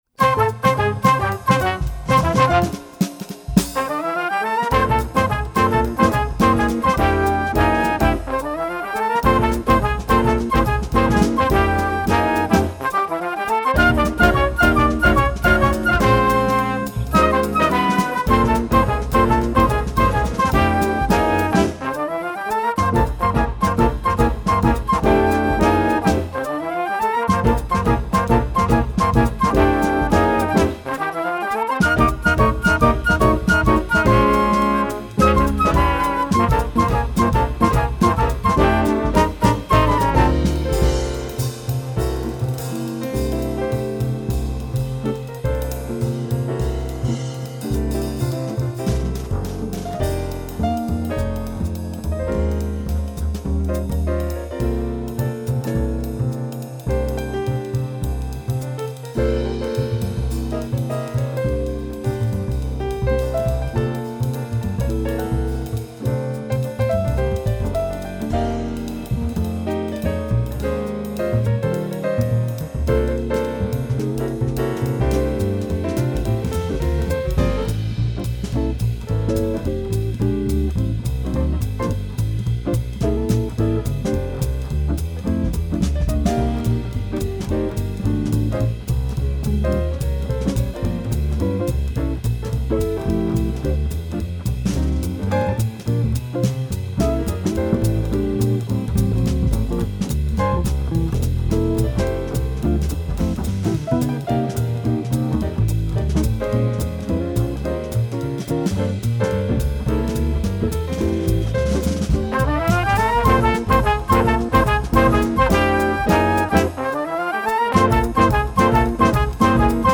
Gattung: Combo
Besetzung: Ensemble gemischt